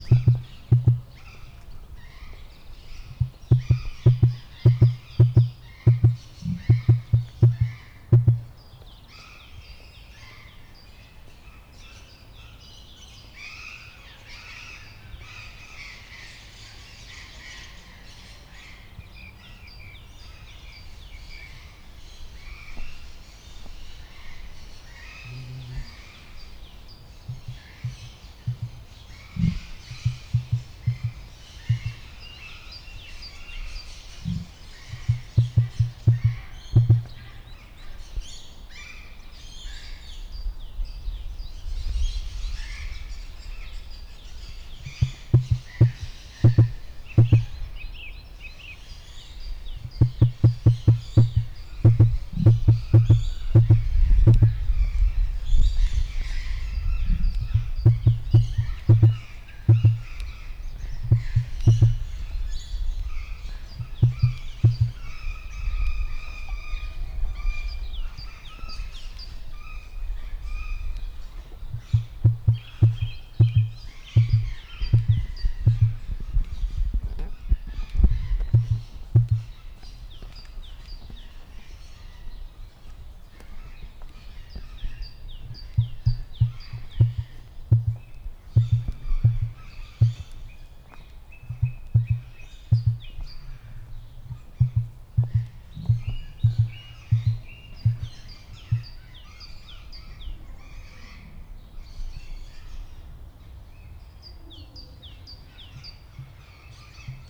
avegefeleeltavolodik_szello_marantzpuska01.47.WAV